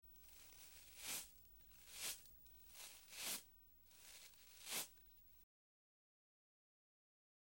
На этой странице вы найдете подборку звуков расчесывания волос – от мягких, едва слышных движений до четких, ритмичных проводок гребнем.